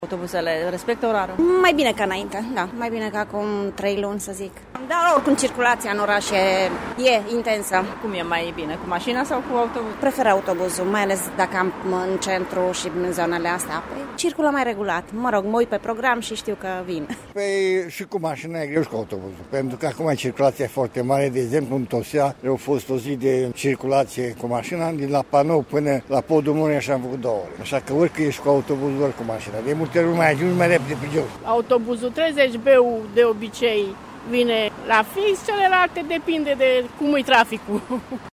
Până la punerea în funcțiune a noilor semafoare inteligente, autobuzele de transport în comun vor înregistra întârzieri, anunță Transport Local Târgu Mureș. Deocamdată, târgumureșenii nu resimt aceste întârzieri și spun că mai bine circulă cu autobuzul decât cu mașina: